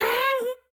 Minecraft Version Minecraft Version snapshot Latest Release | Latest Snapshot snapshot / assets / minecraft / sounds / mob / happy_ghast / ambient14.ogg Compare With Compare With Latest Release | Latest Snapshot